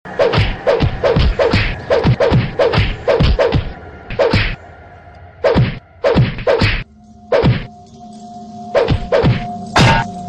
Âm thanh Đánh Nhau